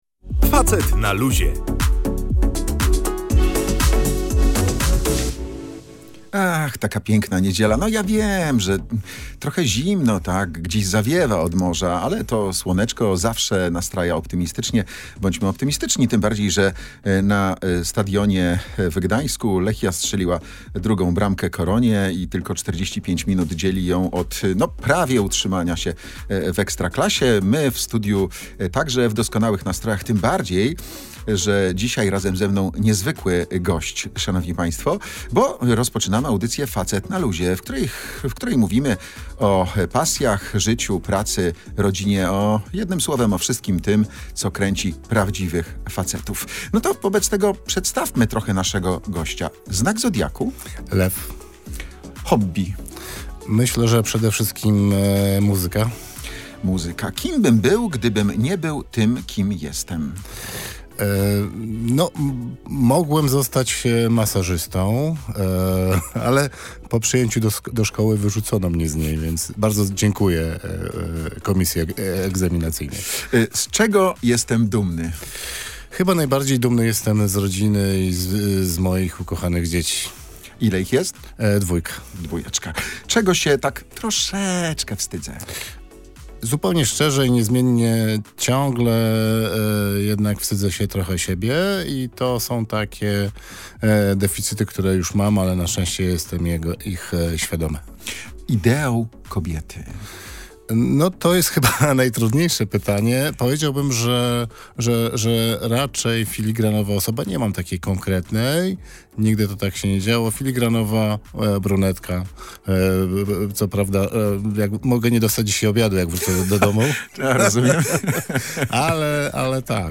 Panowie rozmawiali o różnicach w leczeniu i rehabilitacji różnych niepełnosprawności, zasiłkach i pomocy, ale także znieczulicy i poniżaniu. Dyskusja dotyczyła też możliwości osób niepełnosprawnych na rynku pracy, programach pomocowych i wielkiej roli organizacji pożytku publicznego, które wspierają potrzebujących. Analizowano także polskie przepisy na tle tego, co dzieje się w Europie i o technicznych nowinkach, które mogą pomóc w niektórych schorzeniach.